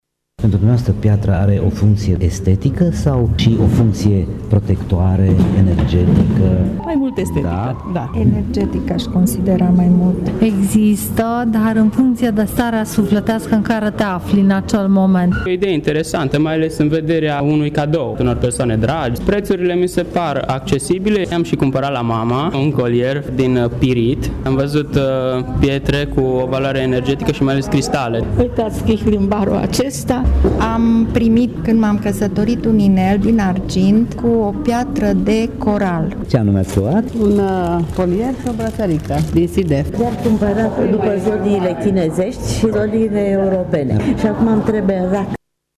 Vizitatorii au declarat că apreciază atât frumusețea cât și valoarea materială a pietrelor, în formă brută sau montate ca bijuterii, dar și funcțiile lor energetice, protectoare.